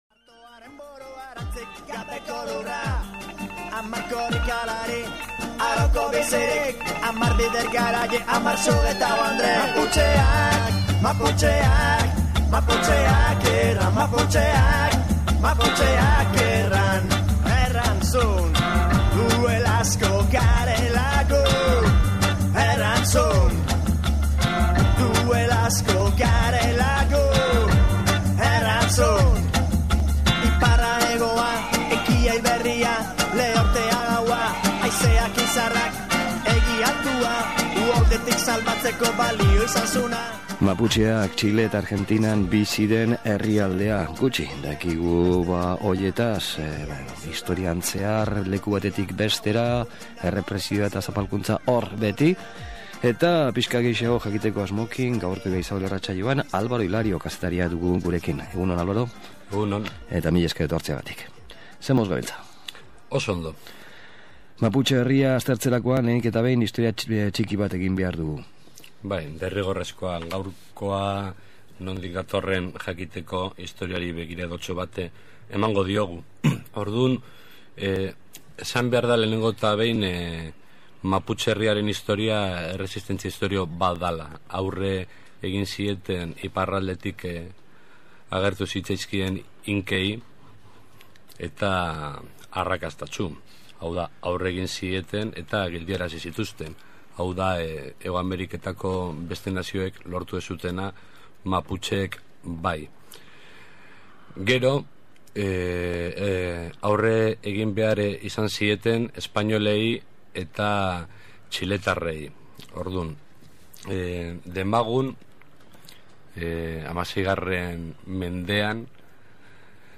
ERREPORTAJEA: Maputxe herria